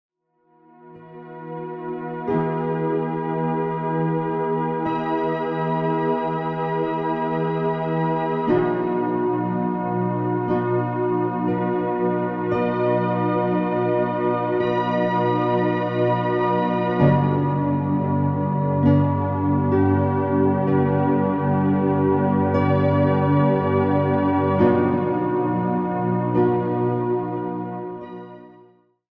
Abgestimmte Musik mit überlagerter Sinusfrequenz in 963 Hz